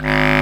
Index of /90_sSampleCDs/Roland L-CDX-03 Disk 1/WND_Lo Clarinets/WND_CB Clarinet